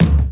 KICK_BERT_2.wav